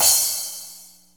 Crash5-44S.wav